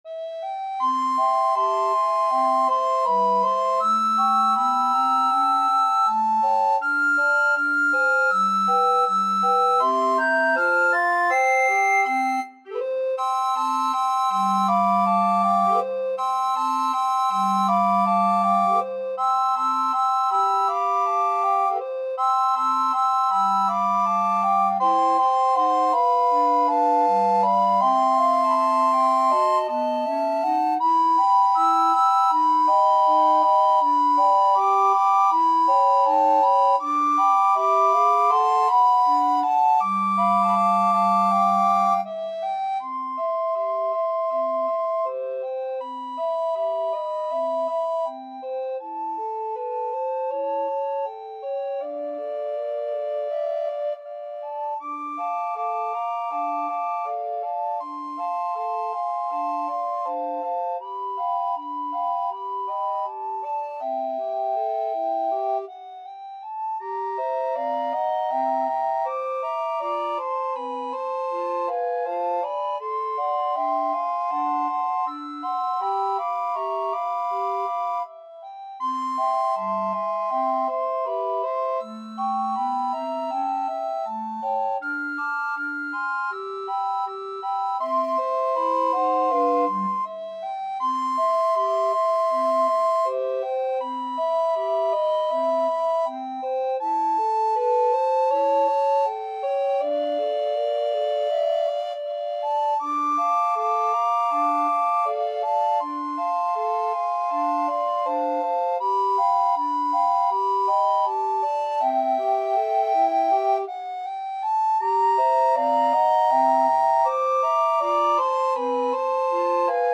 Soprano RecorderAlto RecorderTenor RecorderBass Recorder
2/2 (View more 2/2 Music)
Moderato =80
Pop (View more Pop Recorder Quartet Music)